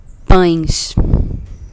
I am a big fan of pães. I am useless at saying it properly myself, but recorded a helper saying it for a post way back last year :)